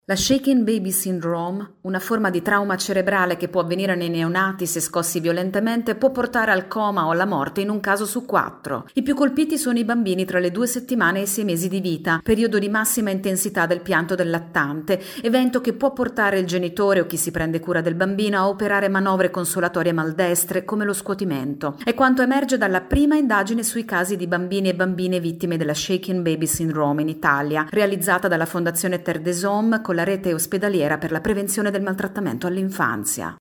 Questa è la campagna di Terre des Homme che ha realizzato la prima indagine sulla sindrome che colpisce i neonati se scossi violentemente. Il servizio